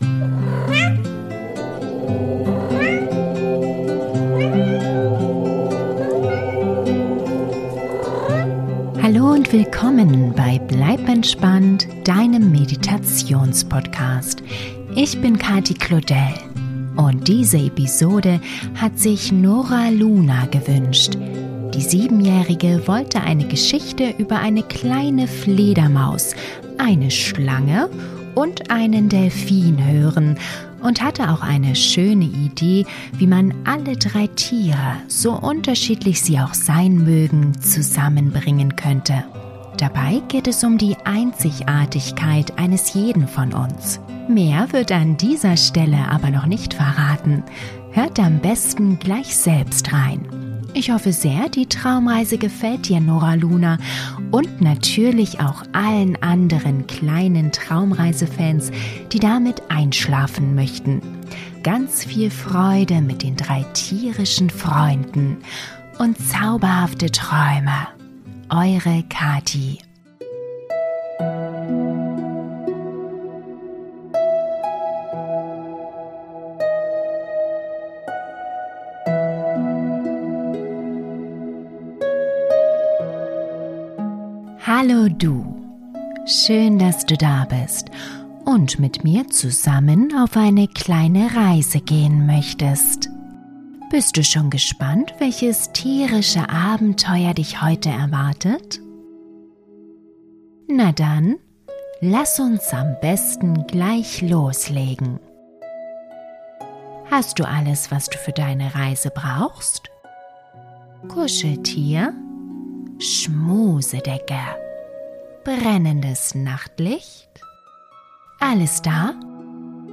Traumreise für Kinder zum Einschlafen - Drei einzigartige Freunde - Selbstliebe & Selbstvertrauen bei Kindern stärken ~ Bleib entspannt!